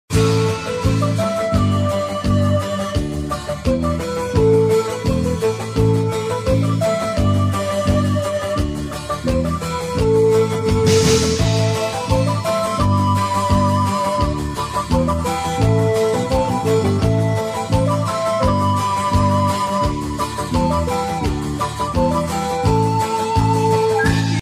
Категория: Спокойные